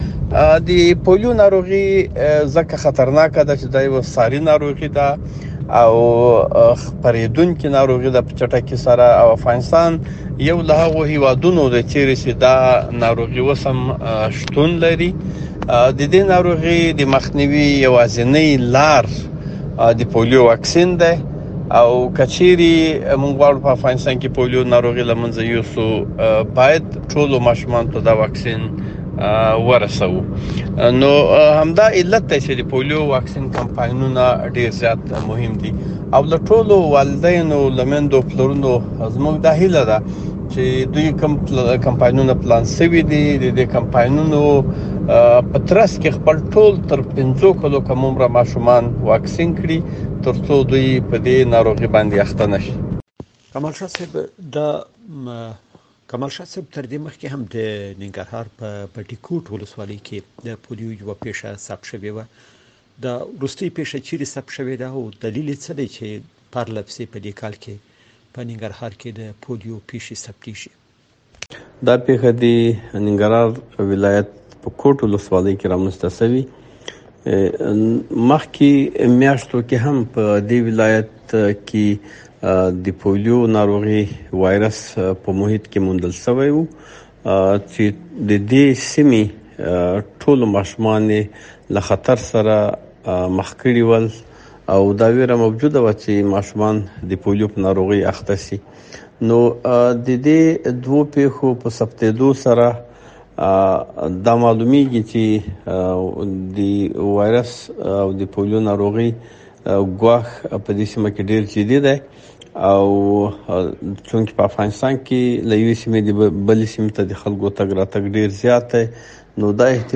مرکه